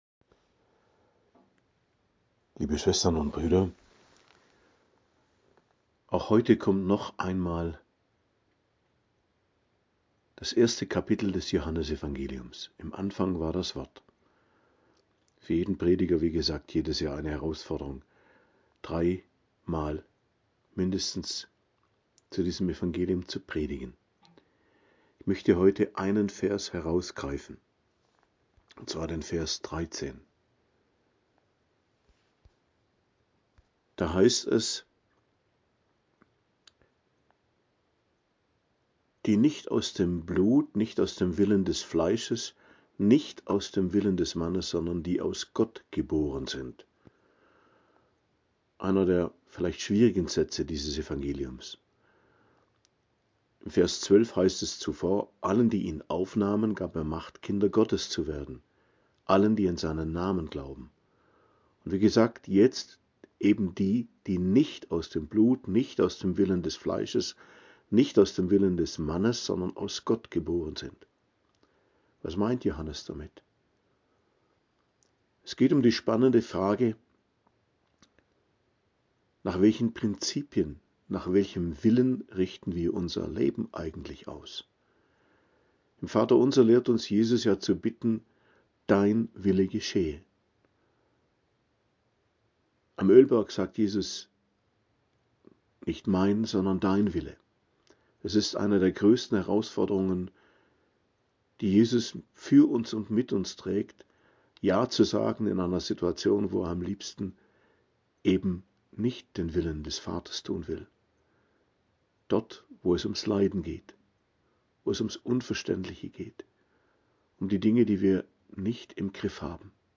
Predigt am 2. Sonntag nach Weihnachten, 5.01.2025